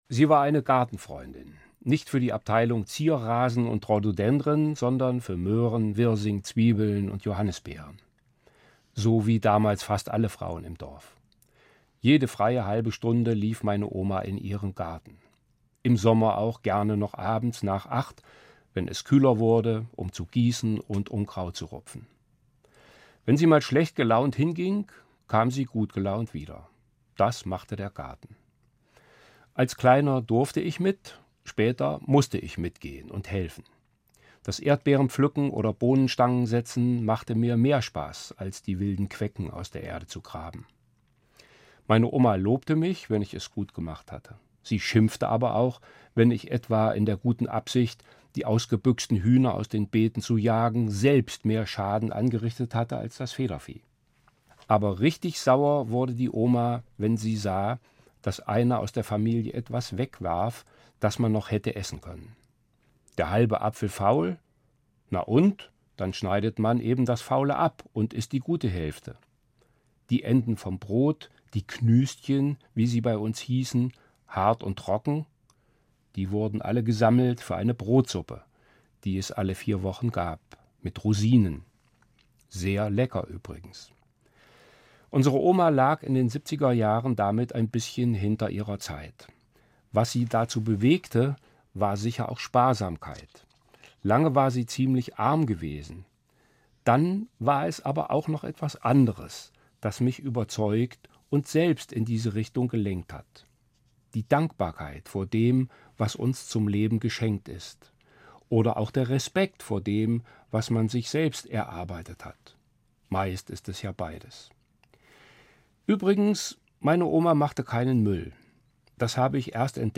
Evangelischer Pfarrer, Marburg